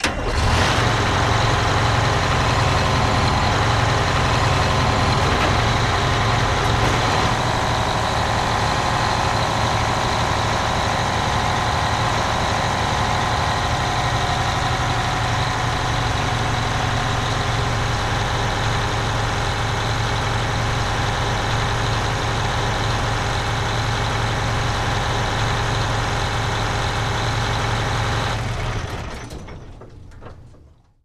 Armored Vehicles
Light armored vehicle starts up, idles then shuts off.